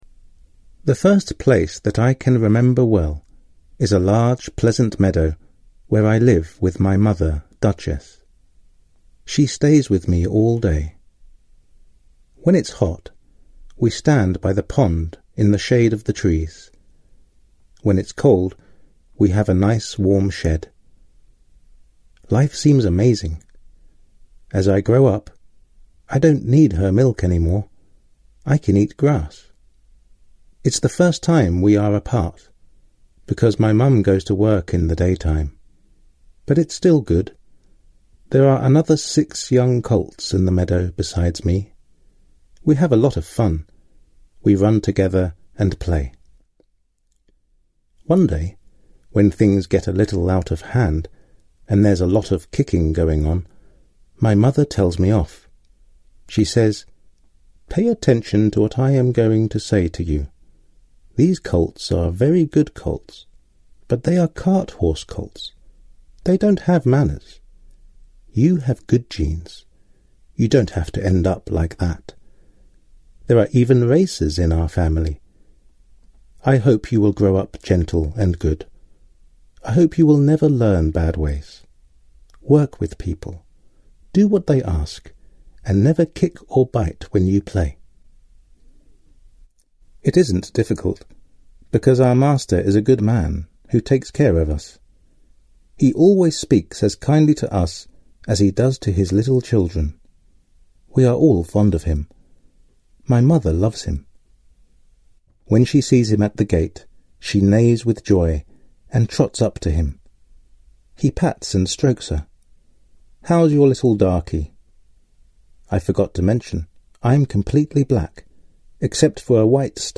Příběh v angličtině namluvil rodilým mluvčím.